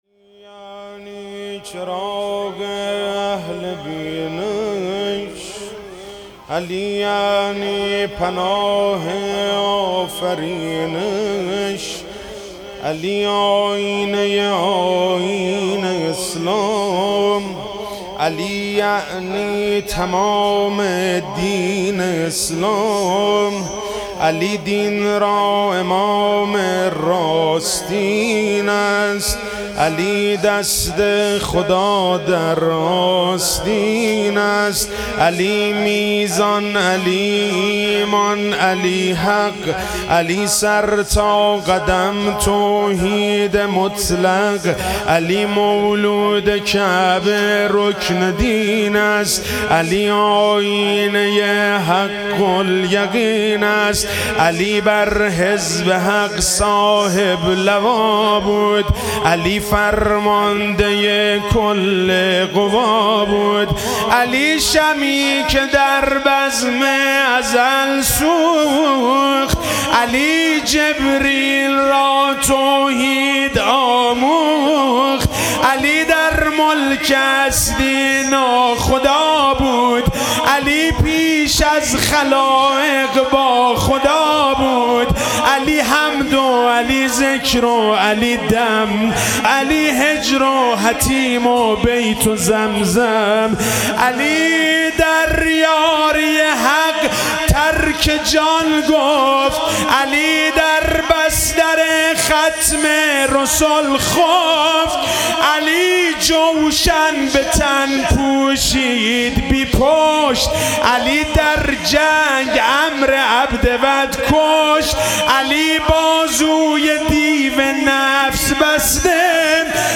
سرود عید غدیر